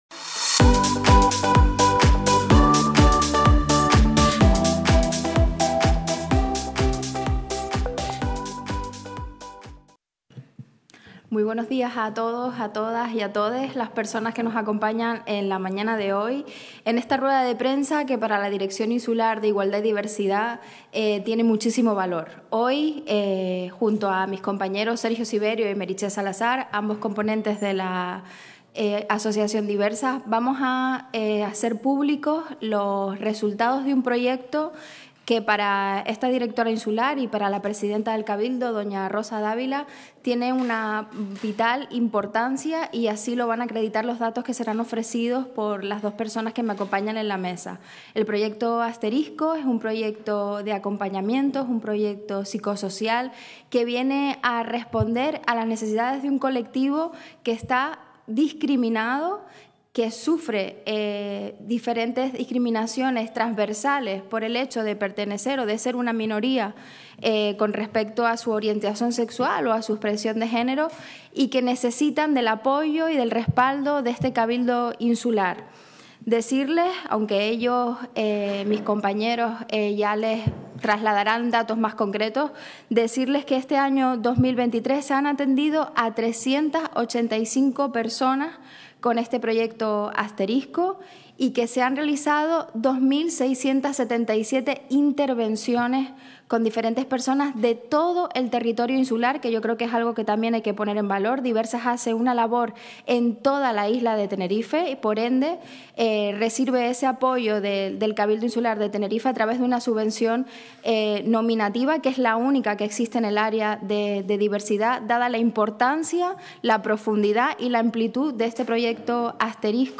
El proyecto Asterisco, puesto en marcha por el colectivo “Diversas” y con la colaboración del Cabildo de Tenerife, ha realizado más de 2.500 intervenciones durante este año. Así se ha informado en rueda de prensa celebrada hoy (lunes) a la que ha...